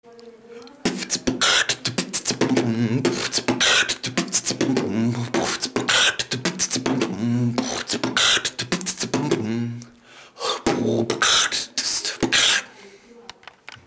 Форум российского битбокс портала » Реорганизация форума - РЕСТАВРАЦИЯ » Выкладываем видео / аудио с битбоксом » Окритикуйте пожалуйста мой бит